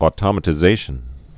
(ô-tŏmə-tĭ-zāshən)